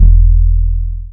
808 (NonStop).wav